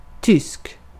Ääntäminen
Synonyymit Teuton (slangi) Kraut Jerry Fritz Hun Teutonic High German Boche Germanic Ääntäminen US UK : IPA : /ˈd͡ʒɜːmən/ US : IPA : /ˈd͡ʒɝmən/ Lyhenteet ja supistumat Germ.